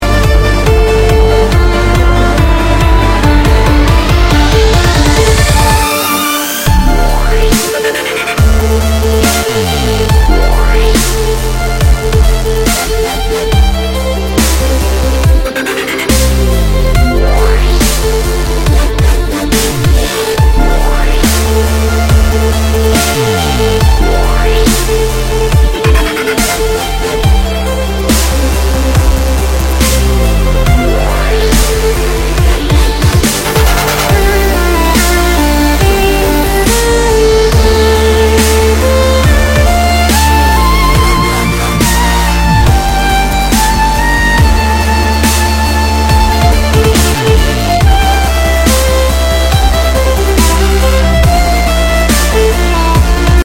• Качество: 128, Stereo
красивые
скрипка